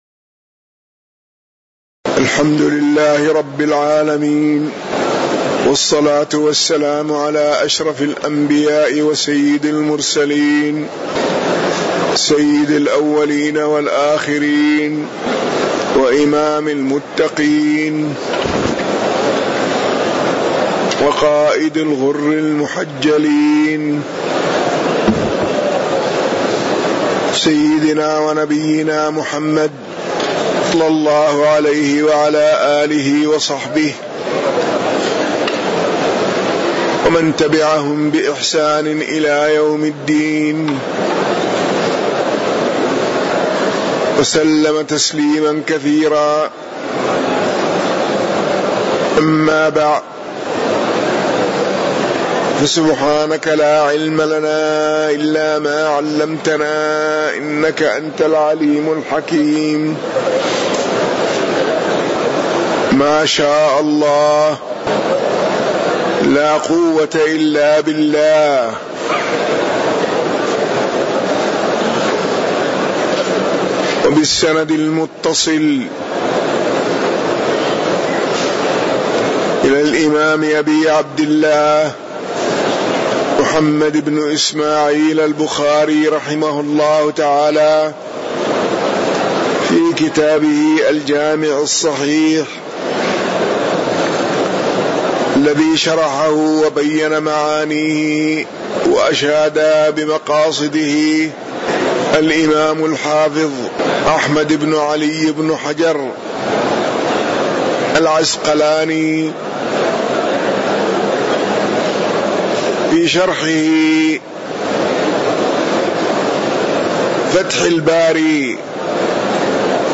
تاريخ النشر ١٧ رجب ١٤٣٩ هـ المكان: المسجد النبوي الشيخ